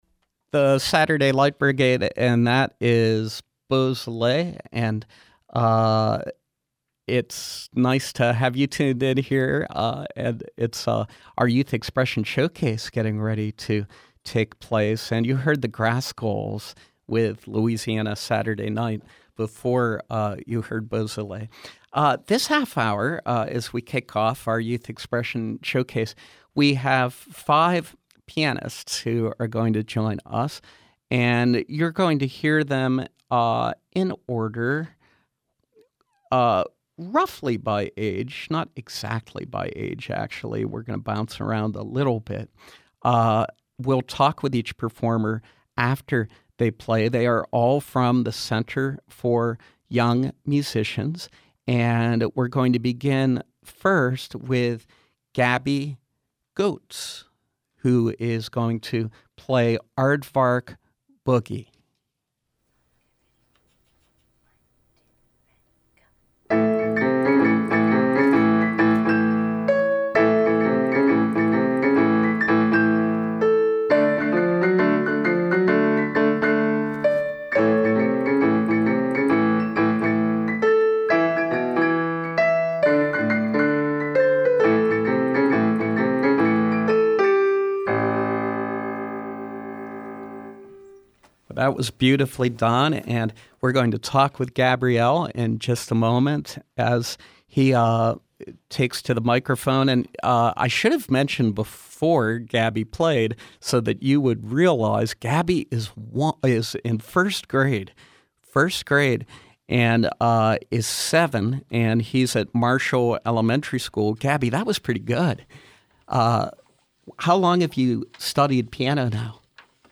Pianists from The Center for Young Musicians. The Center for Young Musicians develops a community of students with a life-long love of music through a comprehensive, long-term curriculum.